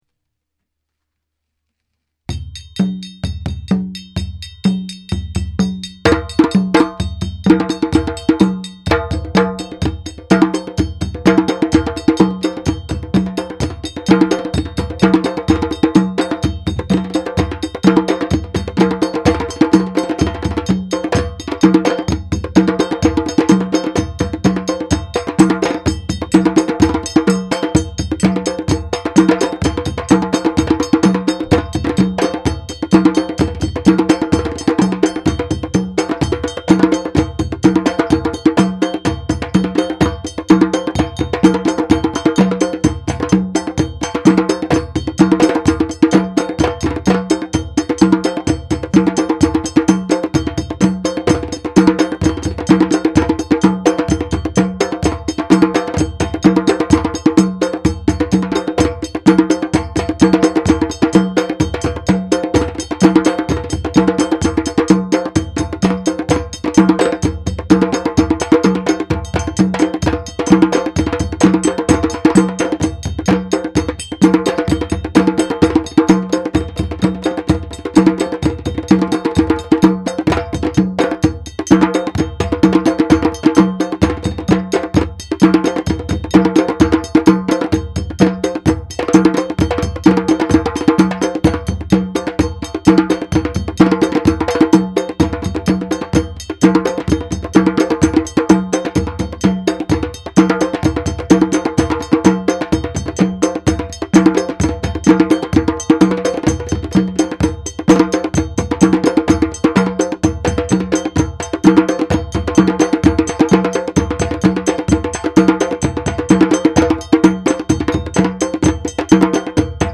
West African rhythm